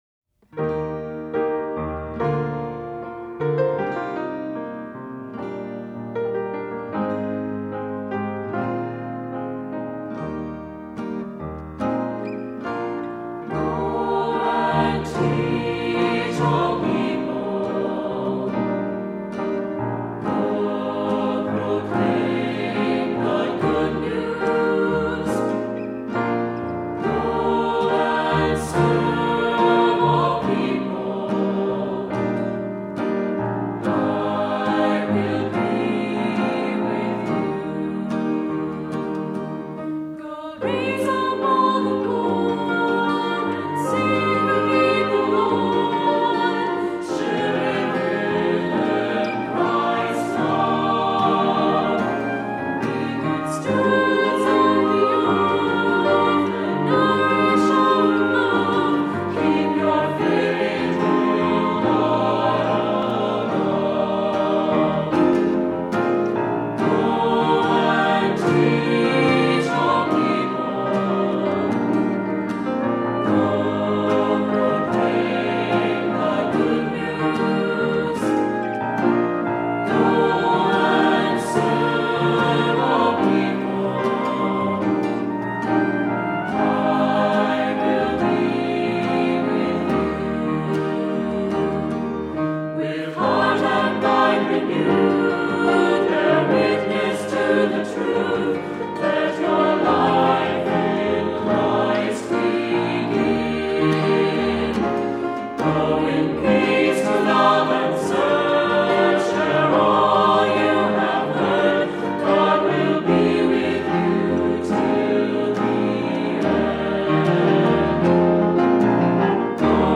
Voicing: SATB; opt. Soprano solo; Assembly